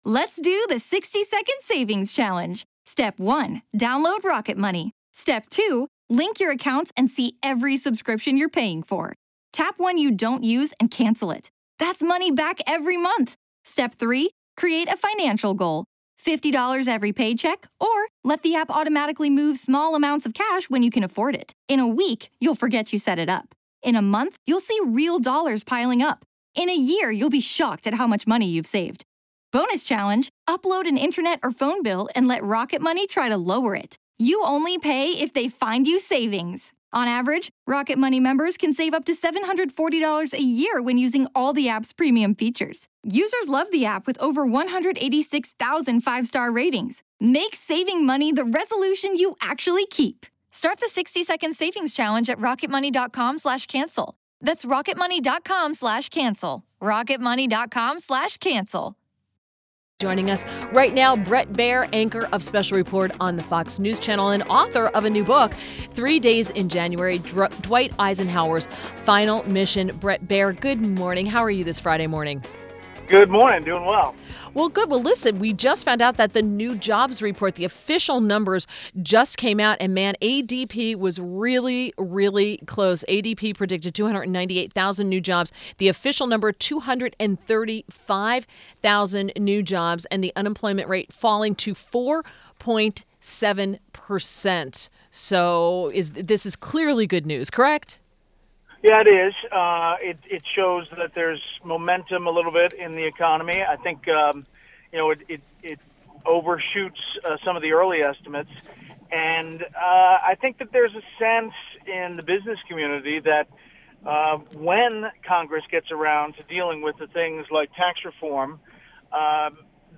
WMAL Interview - BRET BAIER - 03.10.17